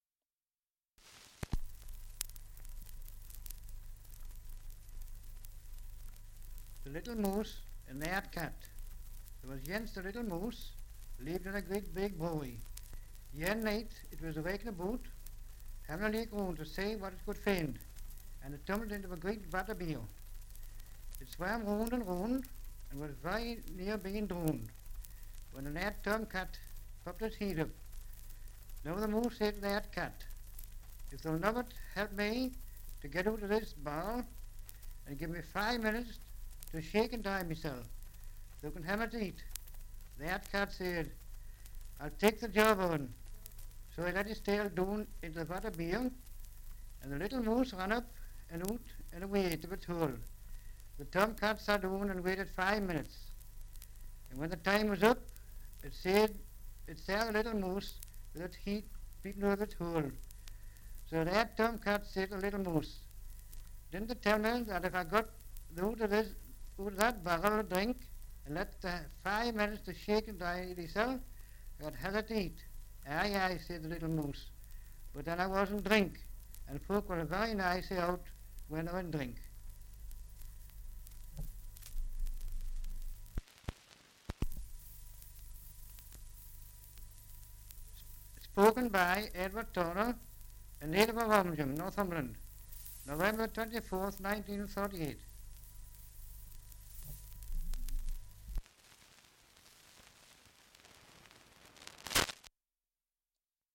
Dialect recording in Ovingham, Northumberland
78 r.p.m., cellulose nitrate on aluminium
English Language - Dialects